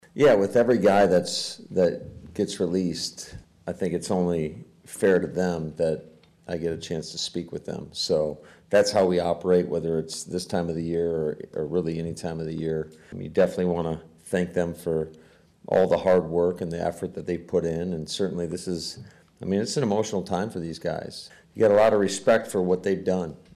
Head Coach Matt LaFleur held a brief media session before the final workout on Ray Nitschke Field and said he plans to personally speak to everyone that has to turn in the playbook.